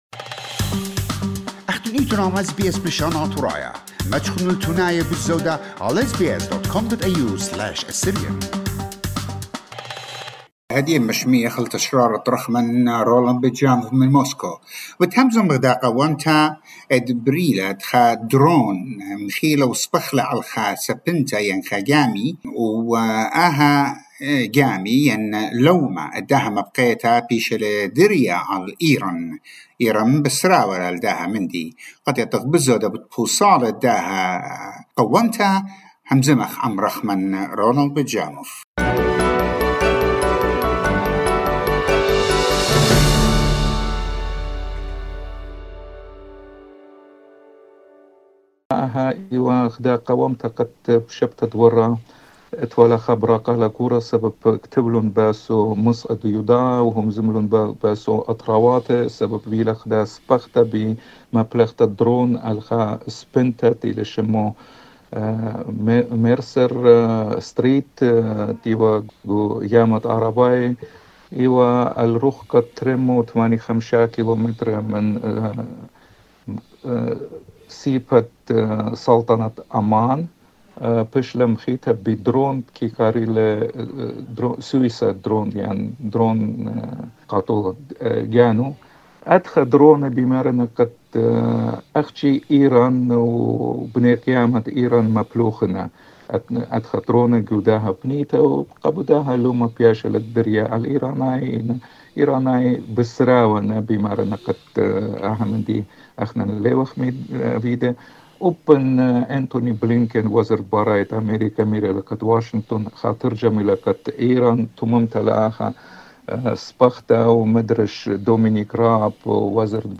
Western countries blame Iran to be behind the attack, but Iran has denied involvement in this incident. More Analysis on the situation in this report